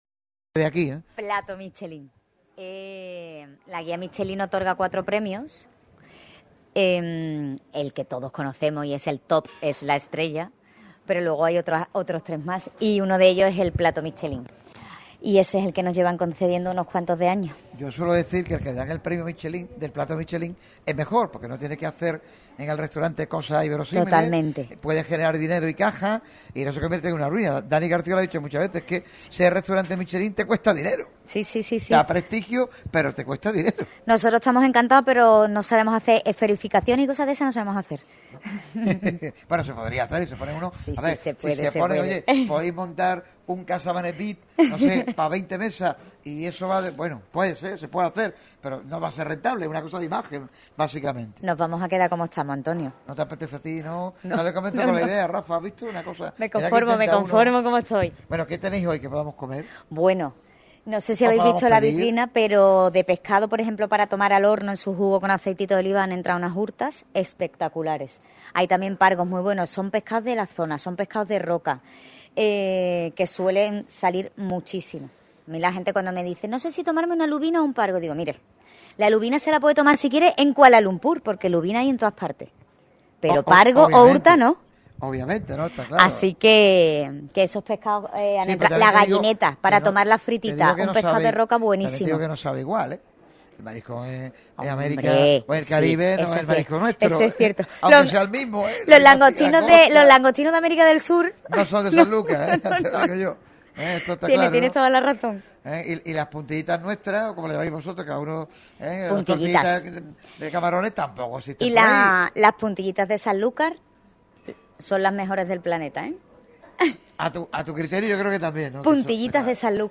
El equipo de Radio Marca Málaga se desplaza este miércoles hasta el restaurante Casa Mané, uno de los rincones por excelencia en Palmones, perteneciente a Los Barrios.